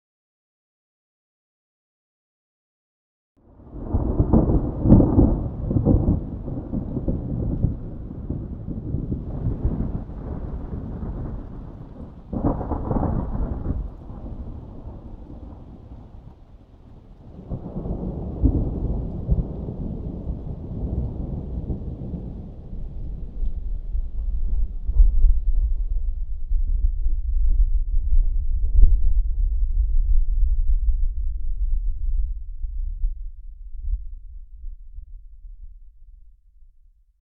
new_thunder2_hec.ogg